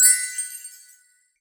chimes_magic_bell_ding_1.wav